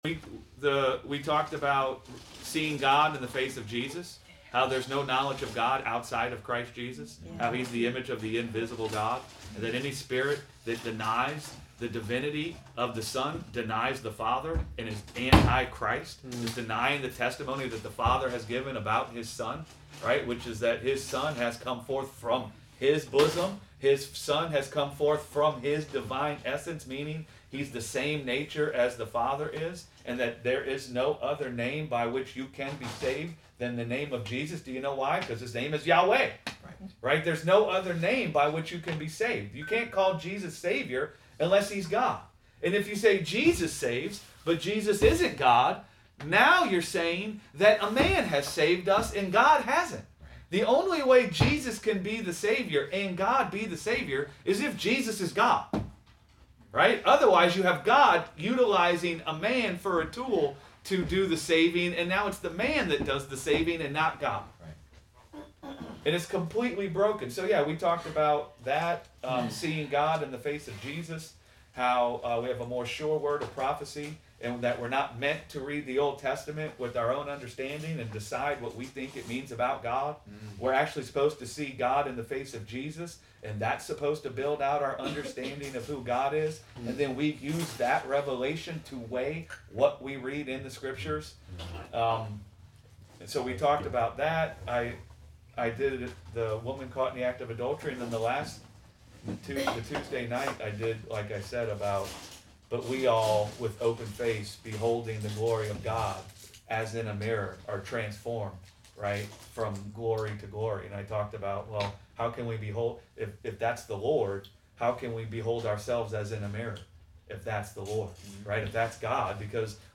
Sunday Bible Study: Crowned with Divinity - Gospel Revolution Church